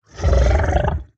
assets / minecraft / sounds / mob / zoglin / idle4.ogg